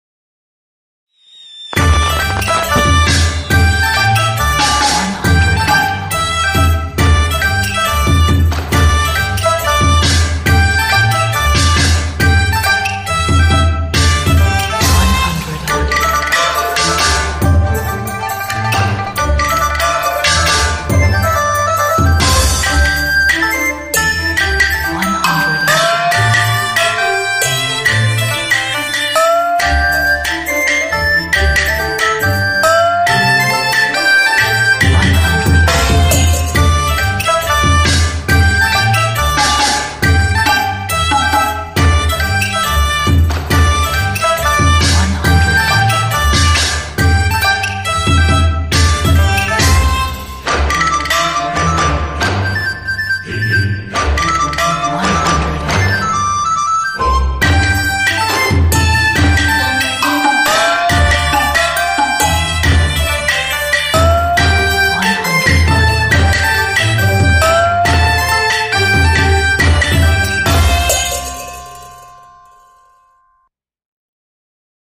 中国民族乐队的配器，渲染出吉祥如意的新年。